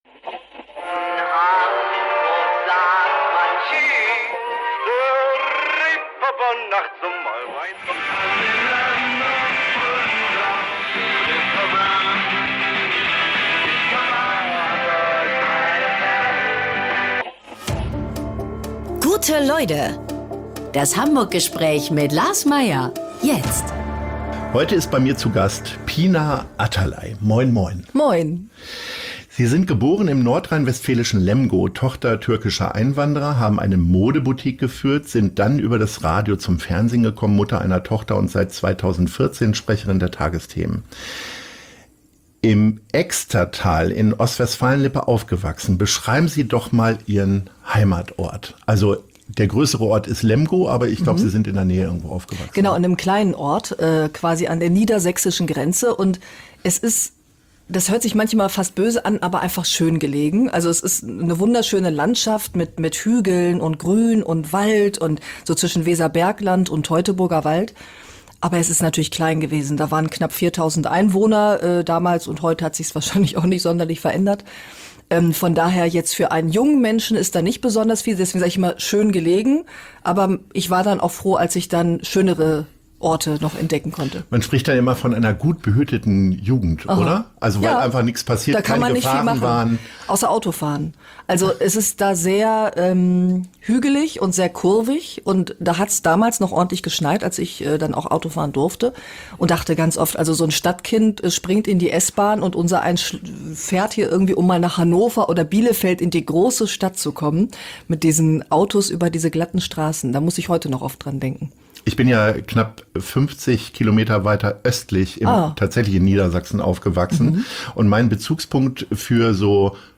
Die Lockerheit merkt man ihr auch sichtlich an, als sie ganz leger gekleidet das Aufnahmestudio betritt.